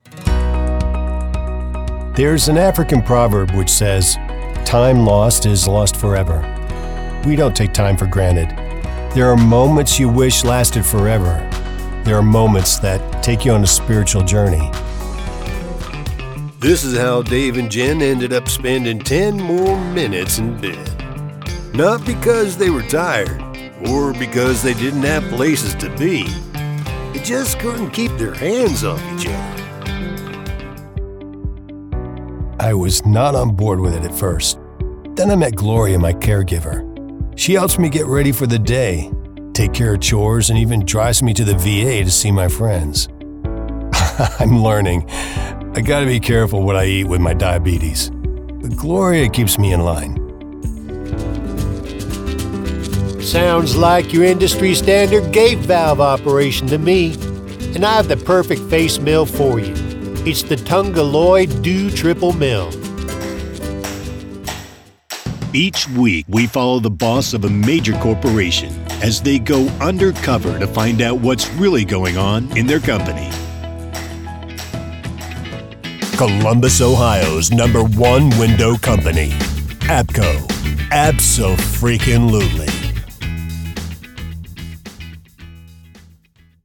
0529Mature_Variety_Demo.mp3